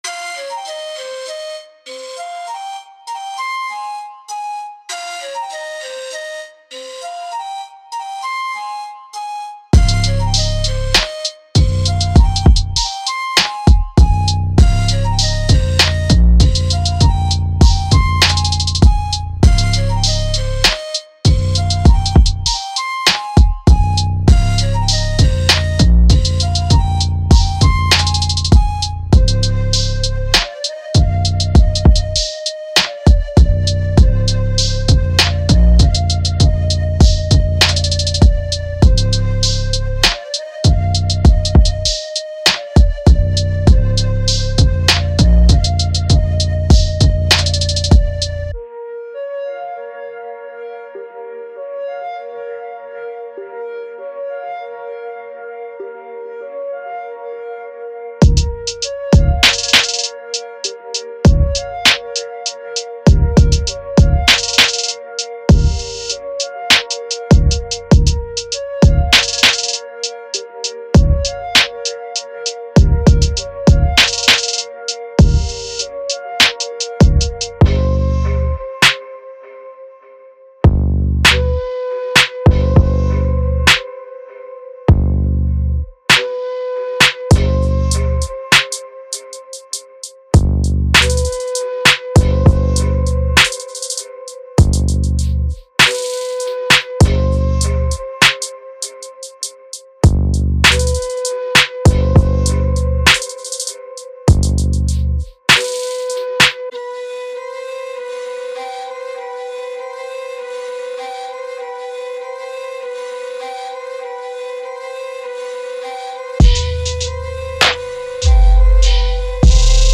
长笛正在成为当今音乐中的 Hip Hop x Trap x R&B 主打产品，为什么不让制作人有机会准备好循环播放，以便轻松拖放到您选择的 DAW 中！
这些长笛循环使用旋转混响氛围、光饱和度/失真、低音模拟驱动均衡器和压缩以及大量的爱和情感进行处理，为您的节拍添加动态和大量酱汁动作！
所有样本均以 24 位 44 khz 录制，所有样本均免版税用于所有商业用途！
•25 Flute Loops (All Tempo x Key Labeled)